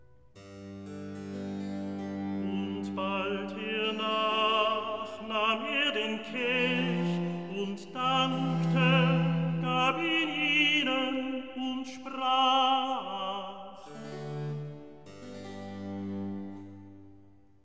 Recitativo evangelist